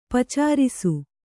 ♪ pacārisu